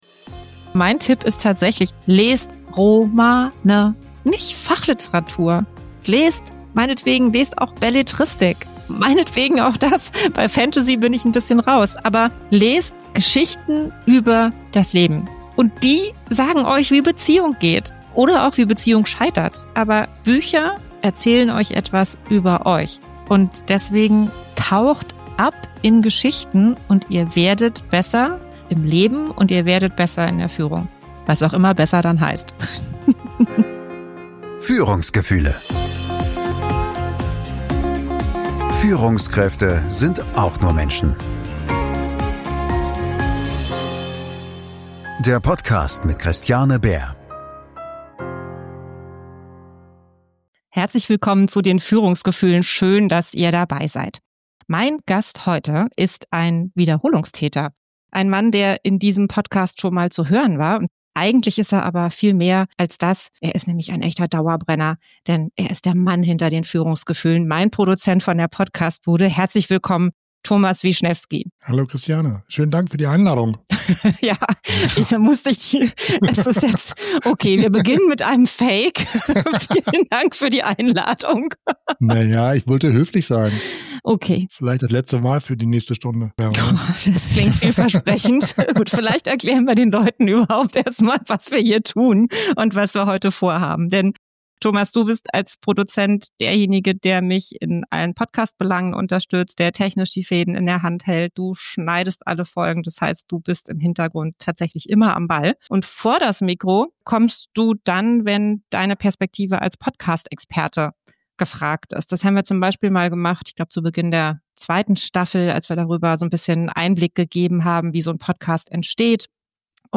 In dieser Jubiläumsfolge sitze ich nicht allein am Mikro, sondern gemeinsam mit